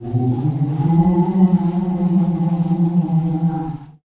Ooo Ghost Sound Effect Free Download
Ooo Ghost